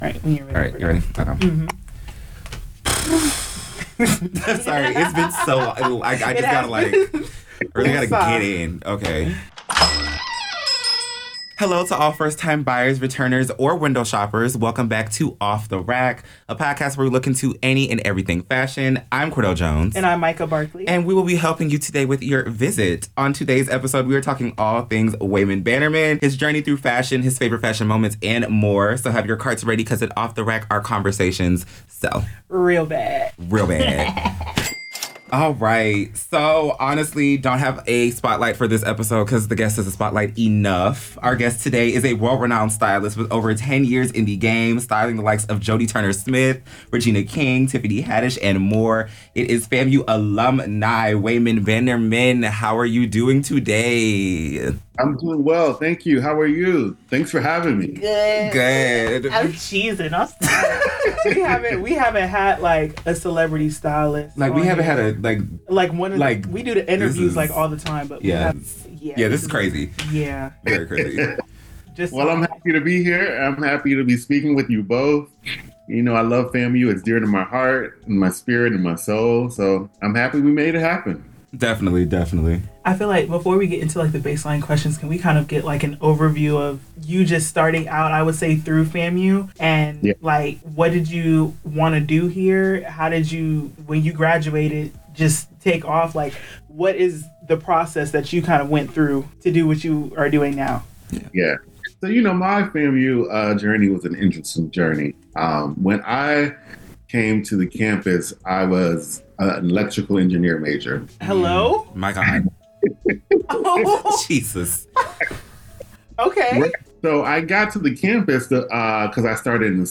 Produced in the WANM-FM 90.5 "The Flava Station" studios at Florida A&M University (FAMU) School of Journalism & Graphic Communication.